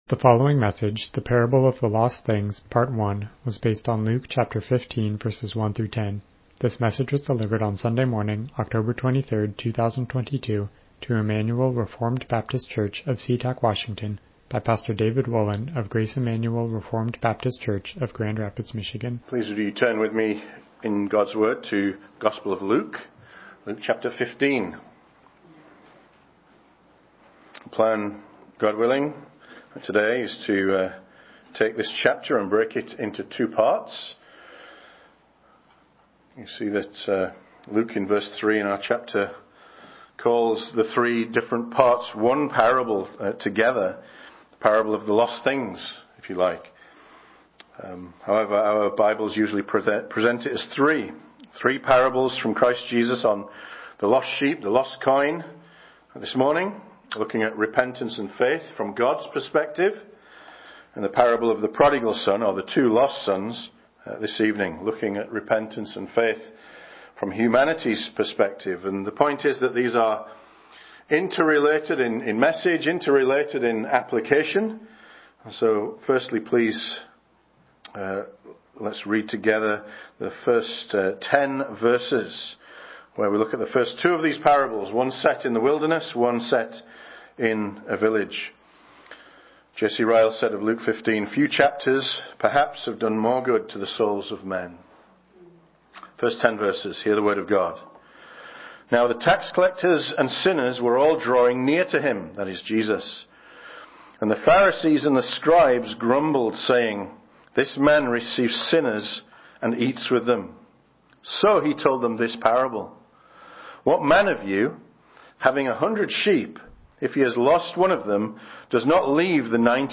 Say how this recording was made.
Passage: Luke 15:1-10 Service Type: Morning Worship « I Am Only a Youth The Parable of the Lost Things